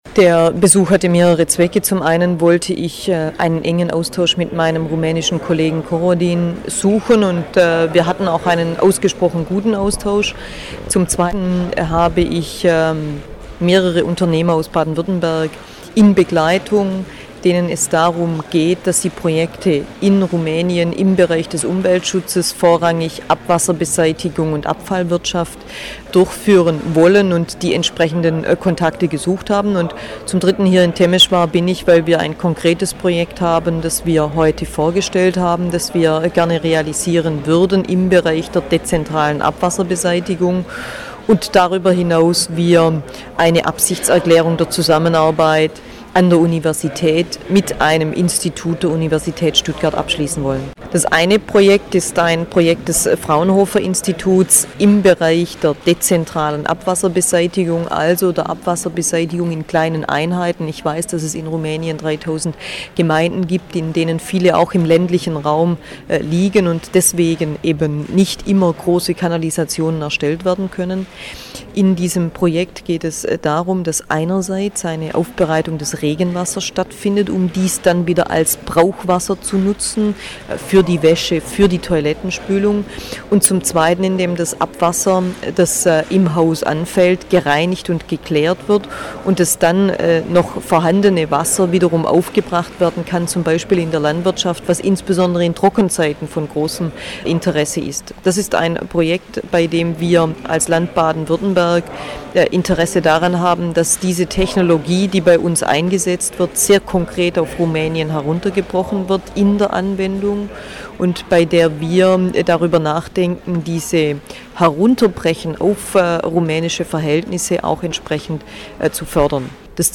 Radiointerview mit Radio Temeswar (Rumänien)